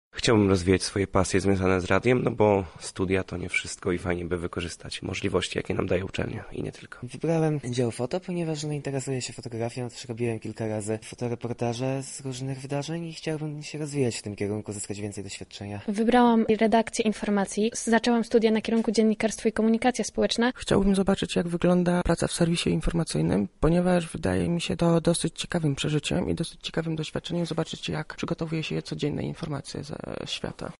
Kandydaci do radia, sonda